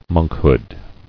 [monk·hood]